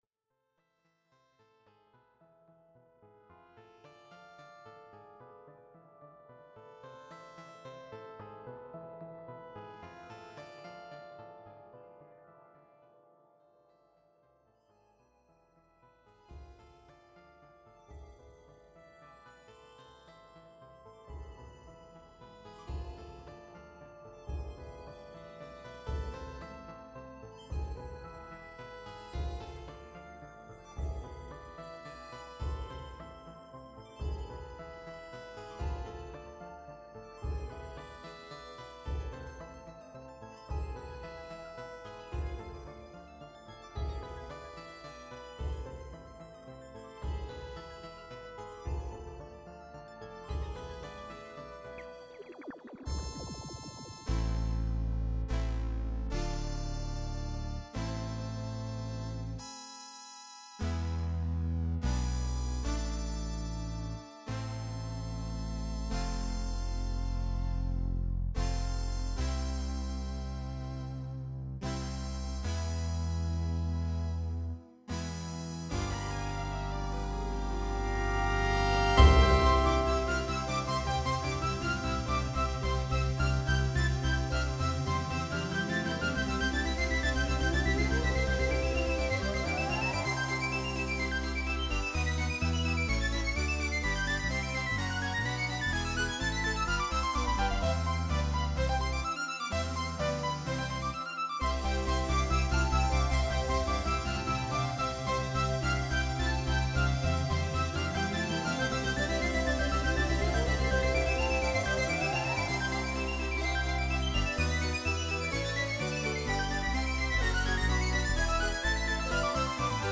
Ambisonic DTS
Ambisonic order : H (3 ch) 1st order horizontal